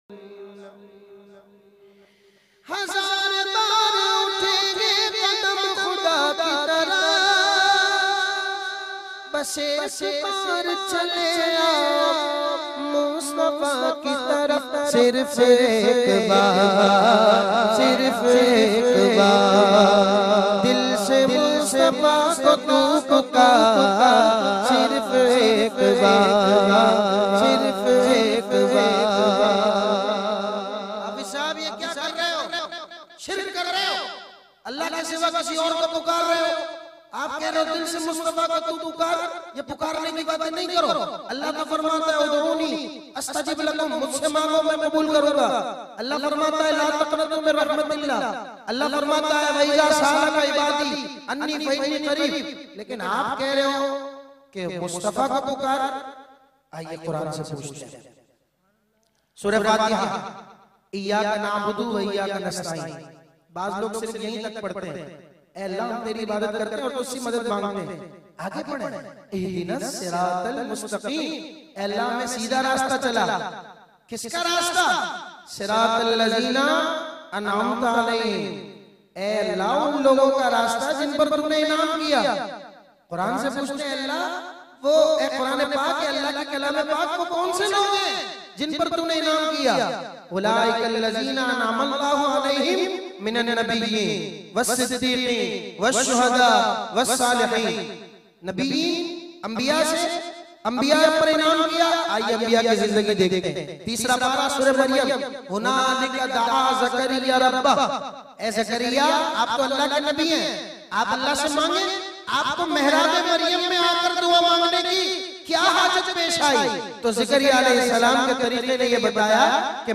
in a Heart-Touching Voice
is a distinguished Islamic scholar and naat khawan.